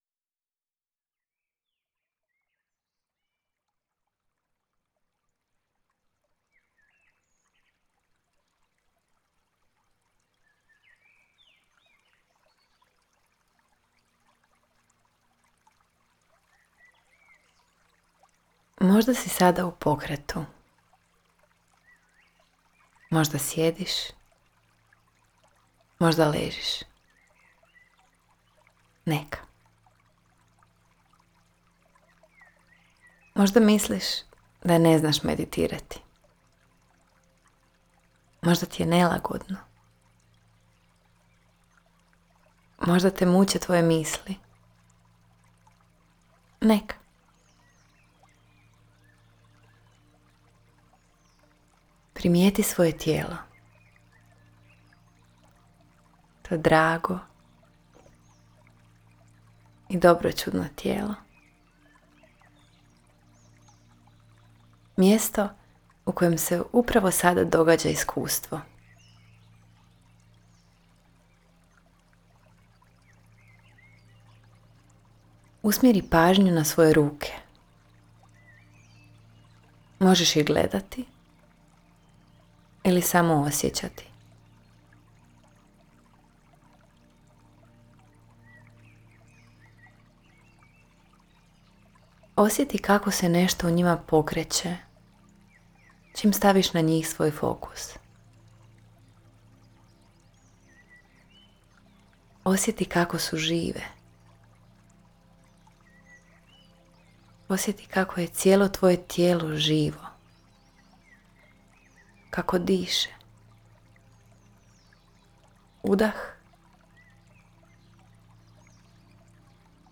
Vođena meditacija: Povratak trenutku